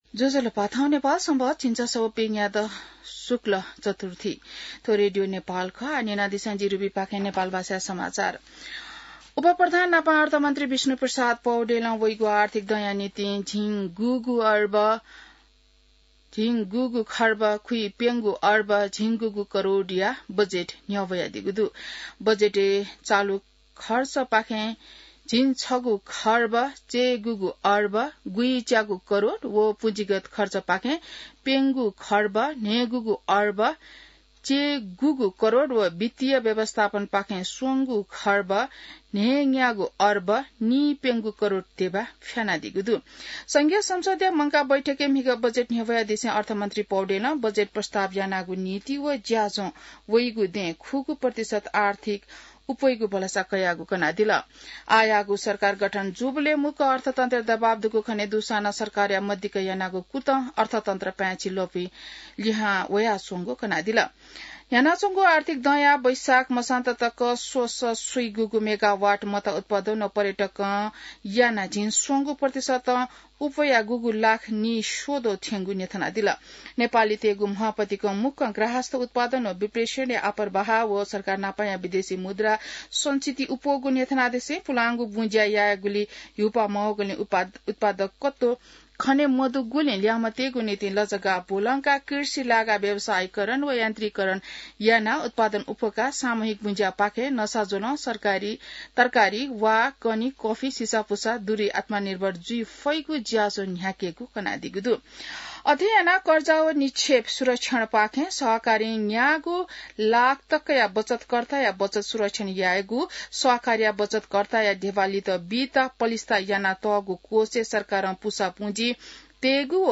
नेपाल भाषामा समाचार : १६ जेठ , २०८२